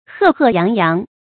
赫赫揚揚 注音： ㄏㄜˋ ㄏㄜˋ ㄧㄤˊ ㄧㄤˊ 讀音讀法： 意思解釋： 赫赫：顯赫的樣子。